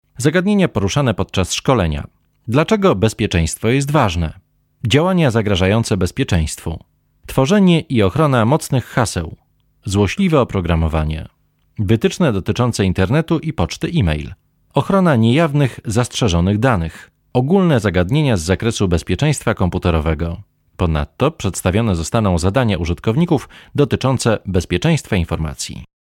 Informative 1